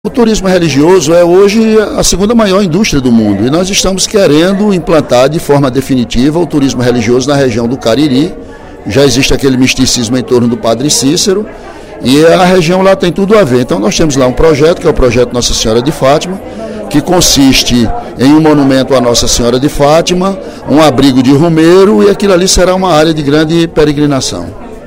O deputado Ely Aguiar (PSDC) pediu, nesta sexta-feira (08/03) durante o primeiro expediente da sessão plenária, apoio do governo do Estado ao Projeto Monumento de Nossa Senhora de Fátima, no Sítio Barro Branco, distrito de Muriti, no Crato, com vistas a incrementar o turismo religioso na região.